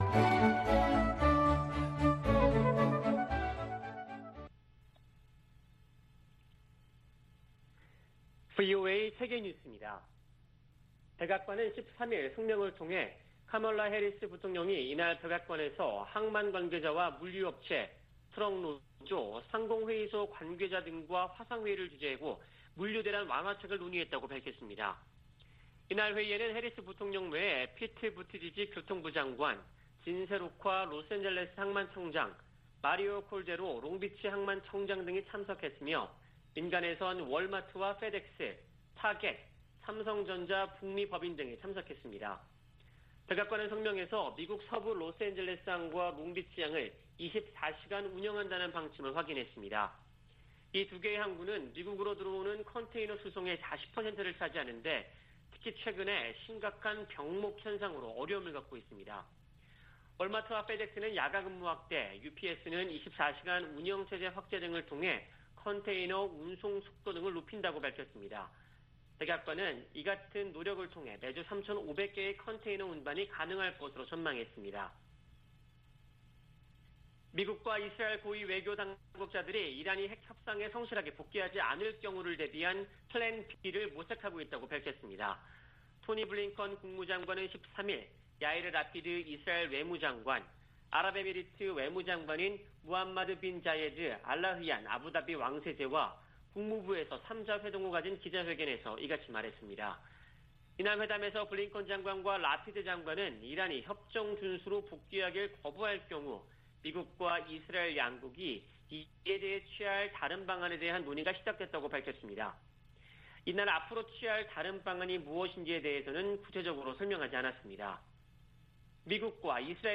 VOA 한국어 아침 뉴스 프로그램 '워싱턴 뉴스 광장' 2021년 10월 14일 방송입니다. 미국이 적대적이지 않다고 믿을 근거가 없다는 김정은 북한 국무위원장 발언에 대해, 미 국무부는 적대 의도가 없다고 강조했습니다. 제이크 설리번 미 국가안보좌관이 워싱턴에서 서훈 한국 국가안보실장과 북한 문제 등을 논의했습니다. 유엔 안보리 대북제재위원회로부터 제재 면제를 승인 받은 국제 지원 물품들이 북한 반입을 위해 대기 상태입니다.